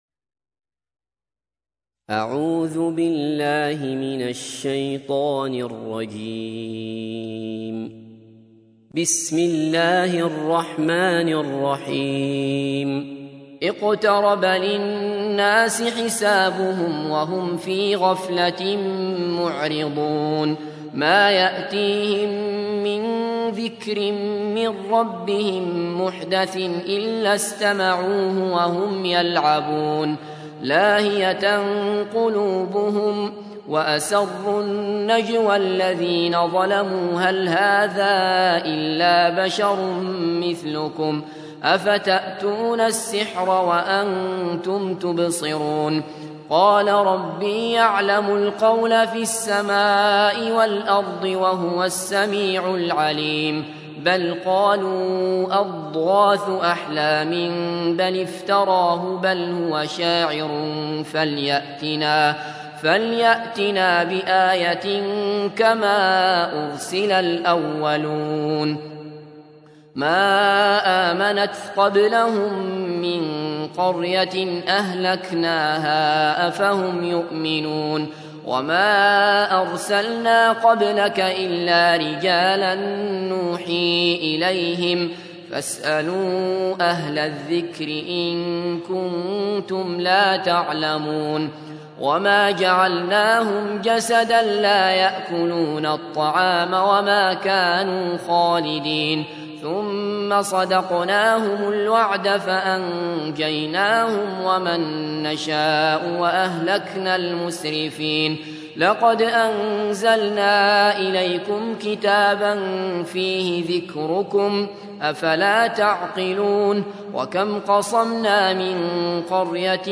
تحميل : 21. سورة الأنبياء / القارئ عبد الله بصفر / القرآن الكريم / موقع يا حسين